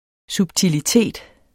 Udtale [ subtiliˈteˀd ]